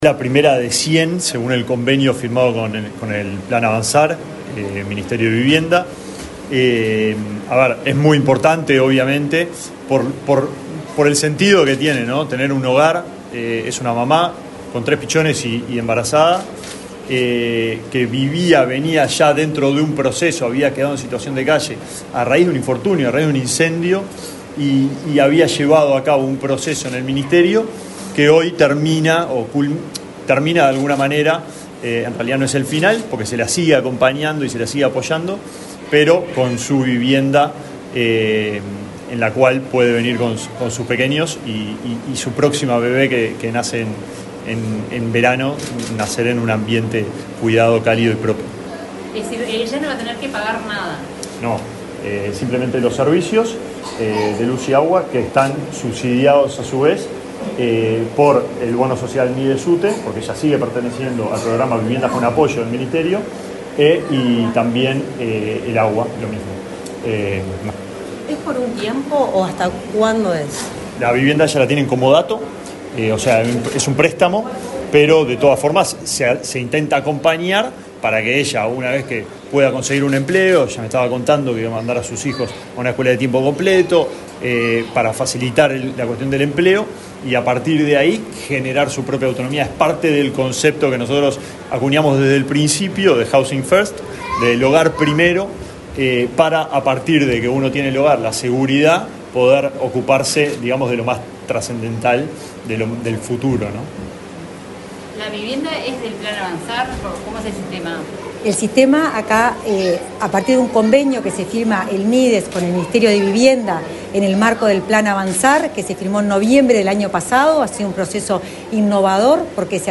Palabra de autoridades del Mides y Ministerio de Vivienda
Palabra de autoridades del Mides y Ministerio de Vivienda 14/11/2024 Compartir Facebook X Copiar enlace WhatsApp LinkedIn El ministro de Desarrollo Social, Alejandro Sciarra, y la titular de la Dirección Nacional de Integración Social y Urbana del Ministerio de Vivienda, Florencia Arbeleche, realizaron la entrega de la primera vivienda para personas en situación de calle, en el marco del Plan Avanzar, ubicada en el barrio Malvín Norte de Montevideo.